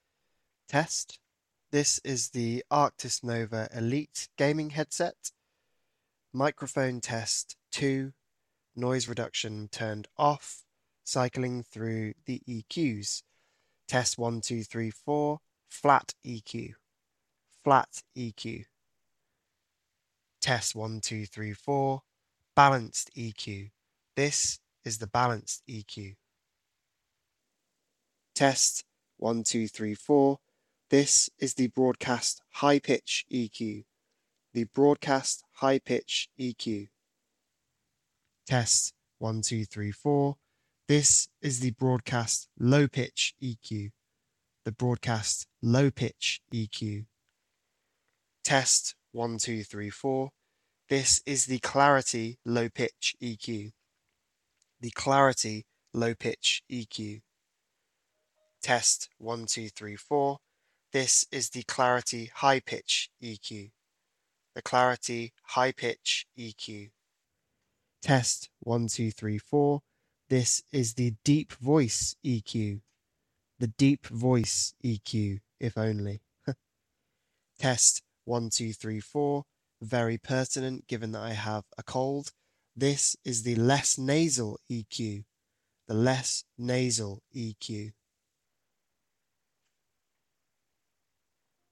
Fairly good microphone
On all my test recordings and on calls with colleagues, my voice is nice and clear. There’s a bit of fuzziness, which none of the microphone EQs helped very much — not even the two broadcast EQ presets — as you can hear in the audio test clip below.
Arctis Nova Elite Mic Test EQ (NR OFF).mp3